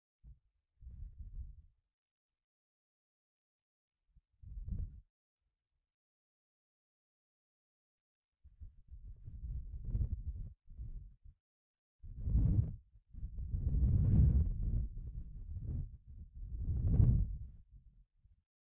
Rain sounds 1
描述：Rain sounds recorded outside my room with an AKG 414 through Apogee Duet. Cars passed by from time to time.Wav file at 24 bit and 96 kHz.
标签： wind cars storm weather bad rain thunder nature fieldrecording
声道立体声